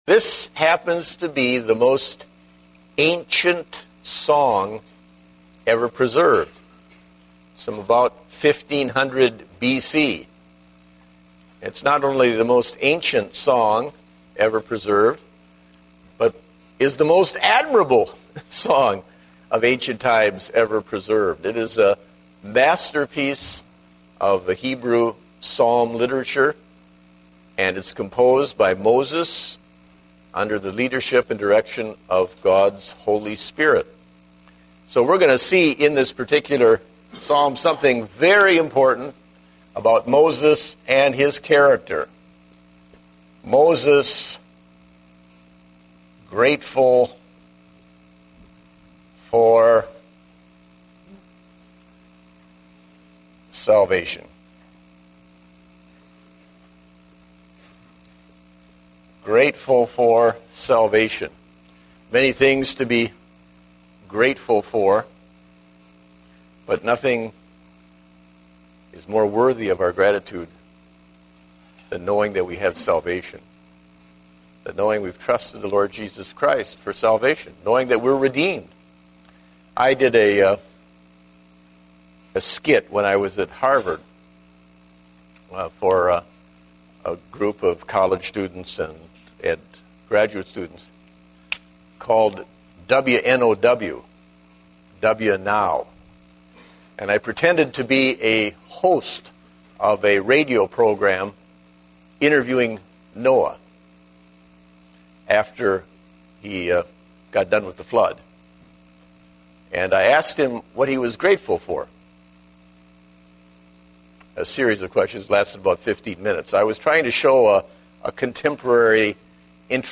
Date: November 28, 2010 (Adult Sunday School)